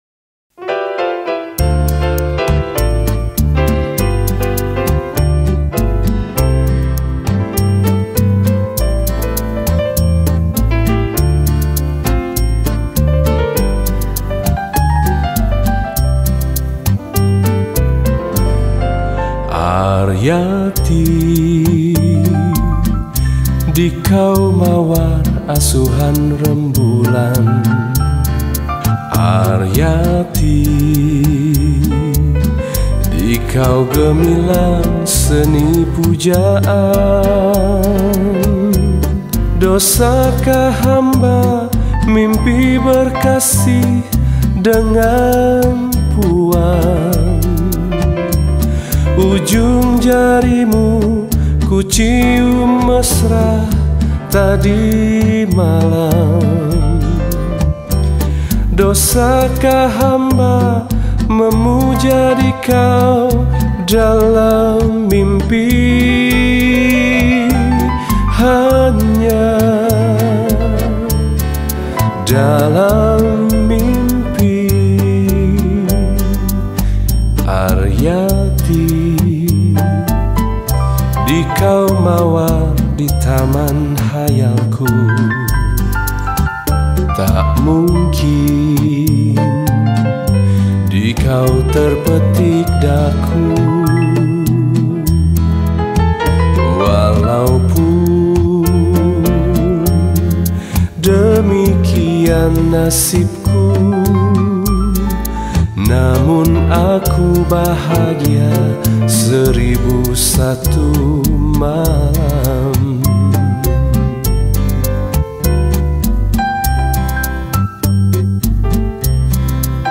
Indonesian Songs
Rekoder Solo Tags
Solo Recorder